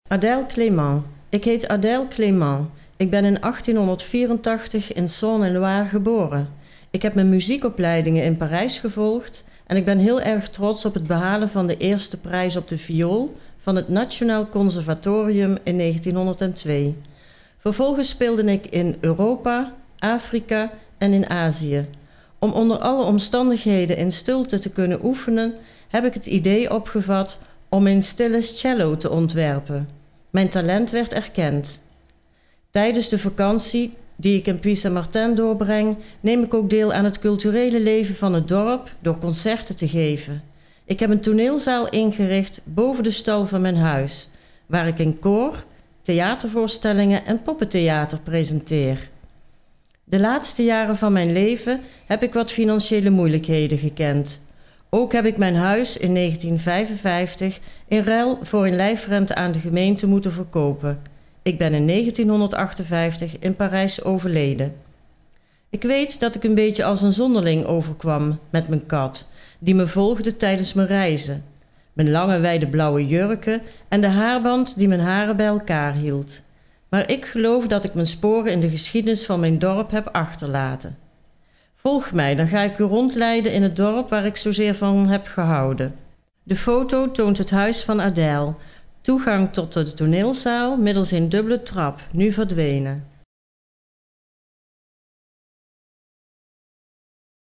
Als de QR-code, zoals hieronder en op elk ander paneel is weergegeven, gescand wordt met een smartphone, zal de bezoeker toegang krijgen tot een audio-gids in het Nederlands, wat zijn bezoek zal verrijken